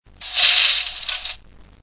Estos sonidos se han grabado directamente del decoder o módulo una vez instalado en la locomotora.
TAFpuerta.mp3